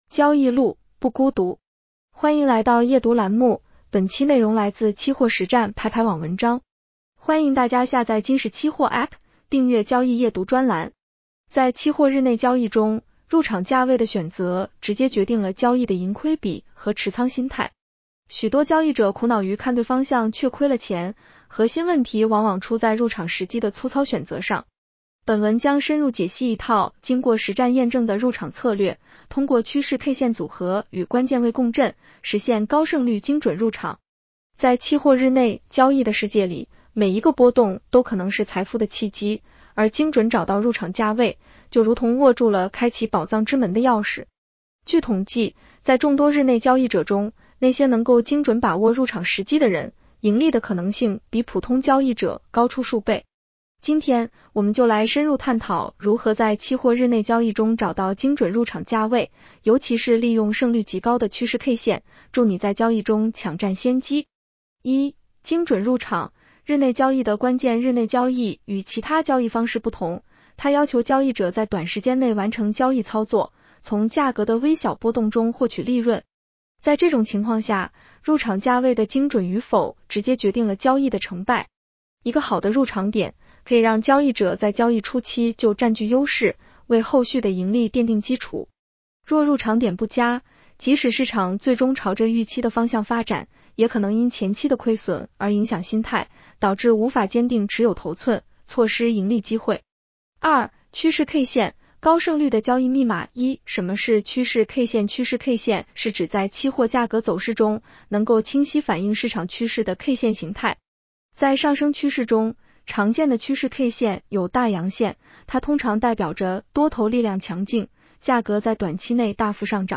女声普通话版 下载mp3 在期货日内交易中，入场价位的选择直接决定了交易的盈亏比和持仓心态。